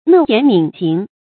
訥言敏行 注音： ㄣㄜˋ ㄧㄢˊ ㄇㄧㄣˇ ㄒㄧㄥˊ 讀音讀法： 意思解釋： 訥言：說話謹慎；敏：敏捷。